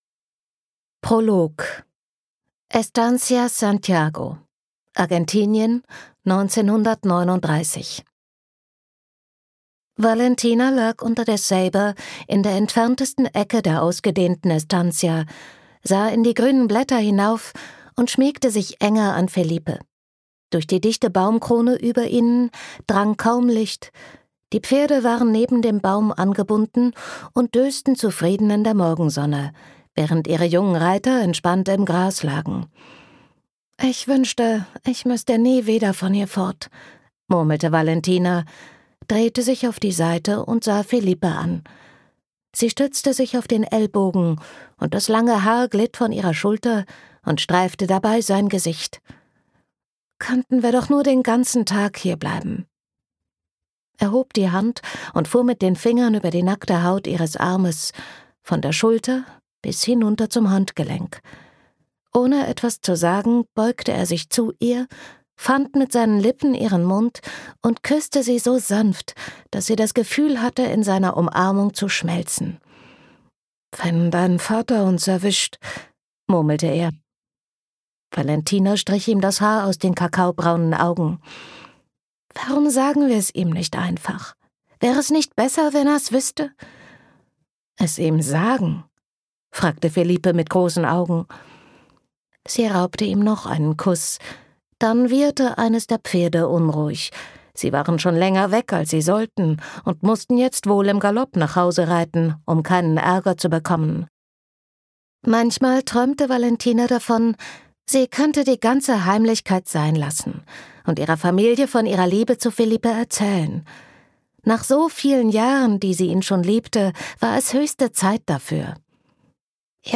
Die verlassene Tochter - Soraya Lane | argon hörbuch
Gekürzt Autorisierte, d.h. von Autor:innen und / oder Verlagen freigegebene, bearbeitete Fassung.